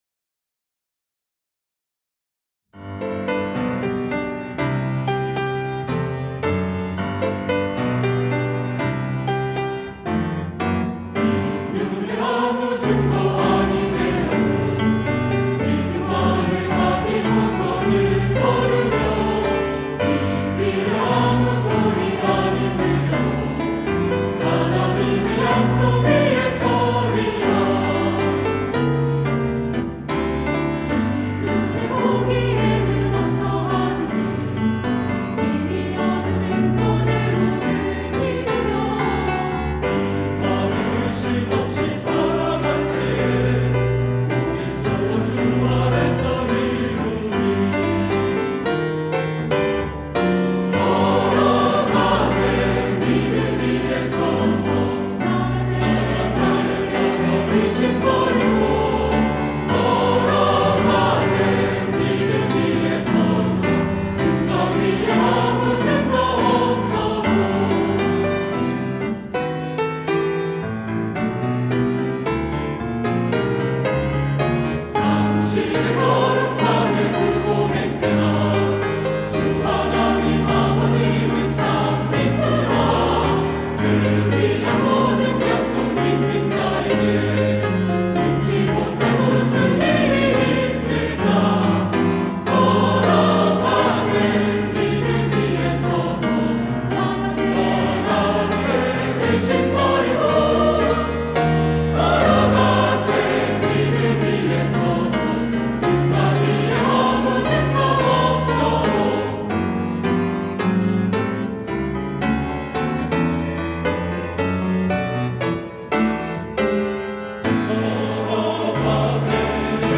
할렐루야(주일2부) - 이 눈에 아무 증거 아니 뵈어도
찬양대